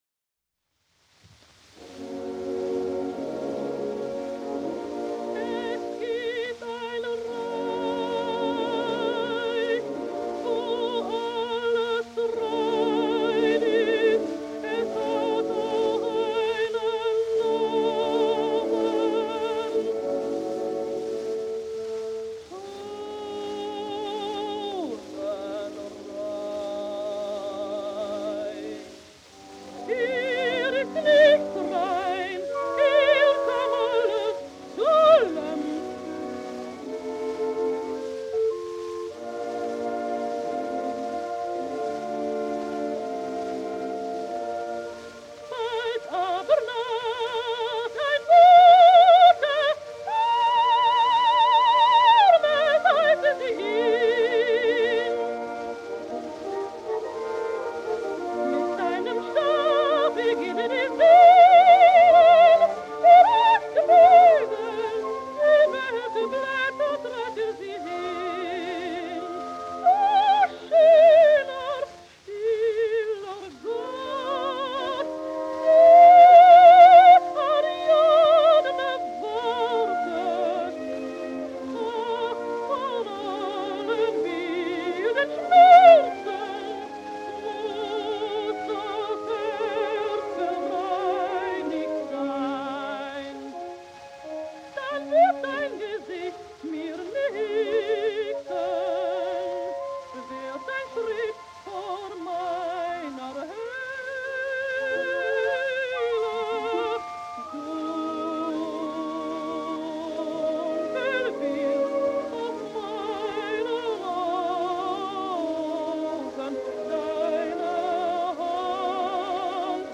sadly cutting out before completion